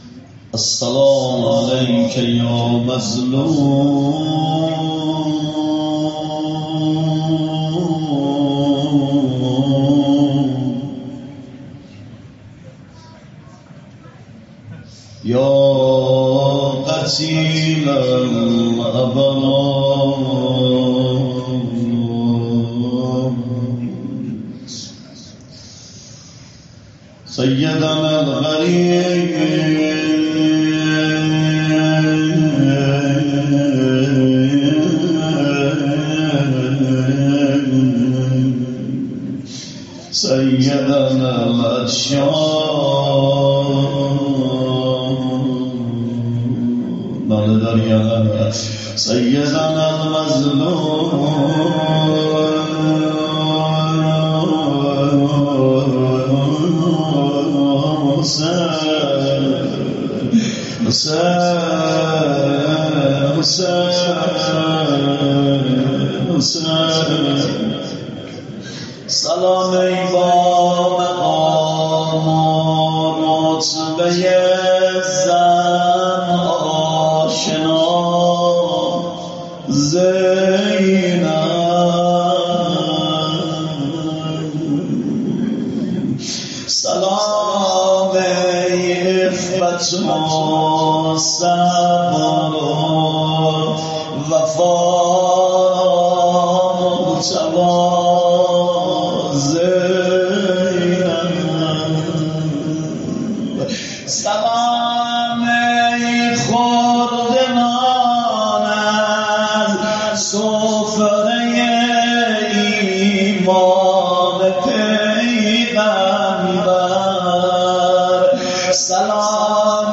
روضه عون و جعفر
امتیاز دهید : روضه عون و جعفر شنبه 31 تیر 1402 ساعت 23:17 شماره فایل: 320920 حجم فایل: 12.49 MB | مدت زمان: 00:21:51 تعداد بازدید: 122 | آخرین بازدید: 7 ساعت پیش بعثه رهبری کربلا معلی حضرت زینب روضه خوانی